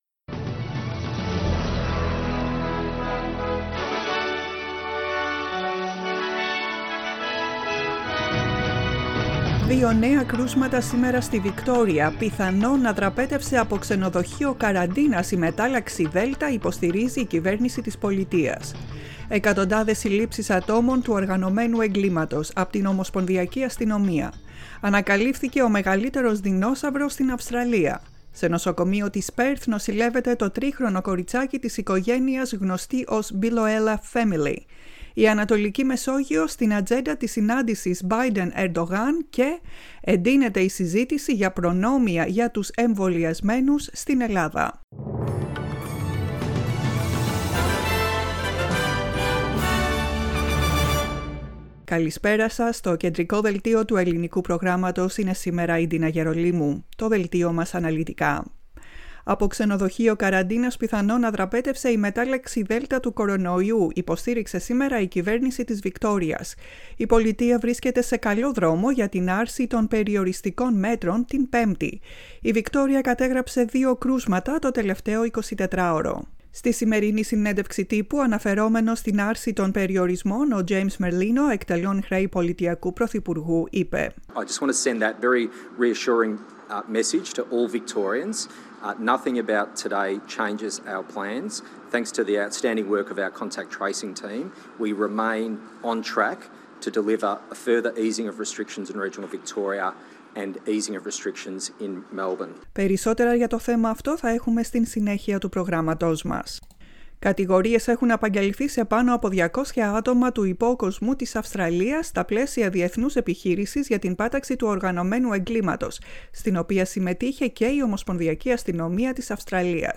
The main bulletin of the day.